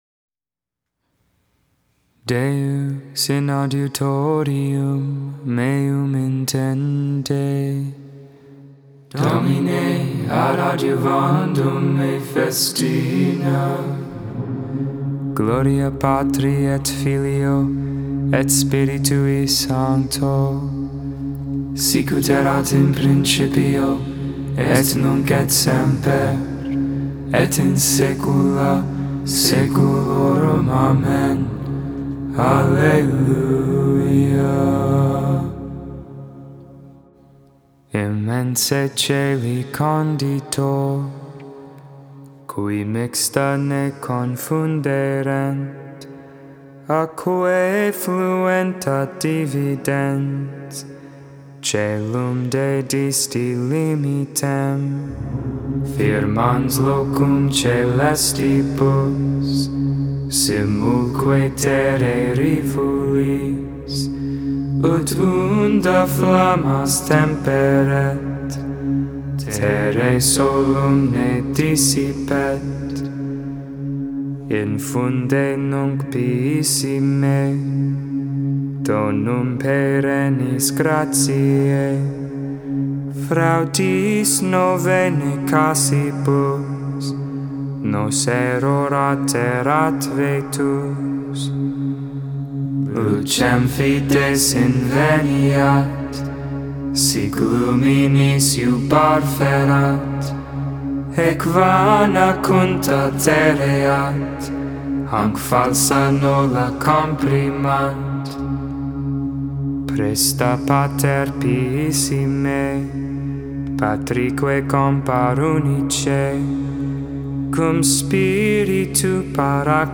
The Liturgy of the Hours: Sing the Hours 9.5.22 Vespers, Monday Evening Prayer Sep 05 2022 | 00:14:56 1x 00:00 / 00:14:56 Subscribe Share Spotify RSS Feed Share Link Embed